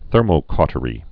(thûrmō-kôtə-rē)